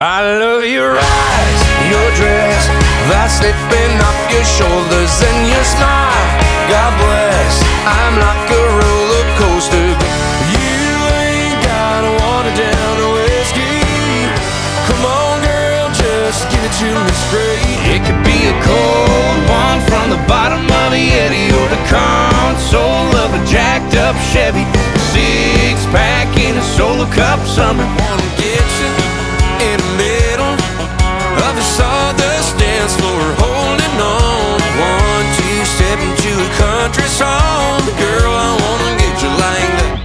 new country